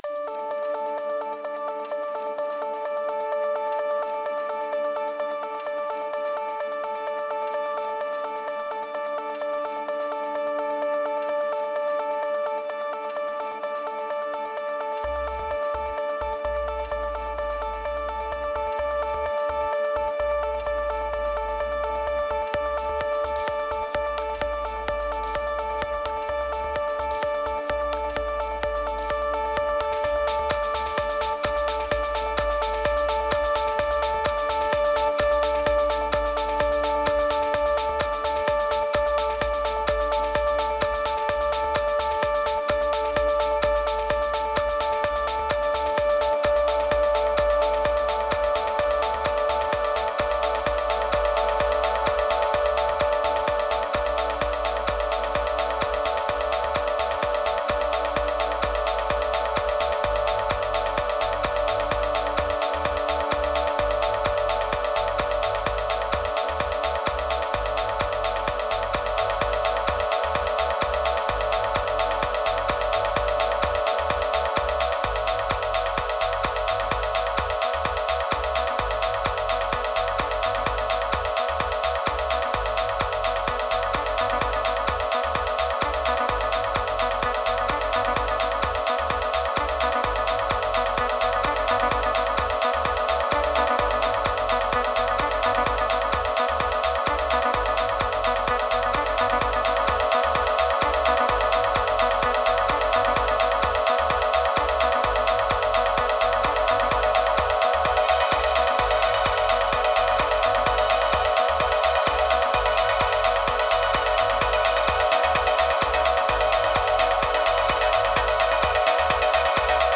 3260📈 - 73%🤔 - 128BPM🔊 - 2024-07-16📅 - 582🌟
Cloudy summer, head banging kicks, different synthesizers.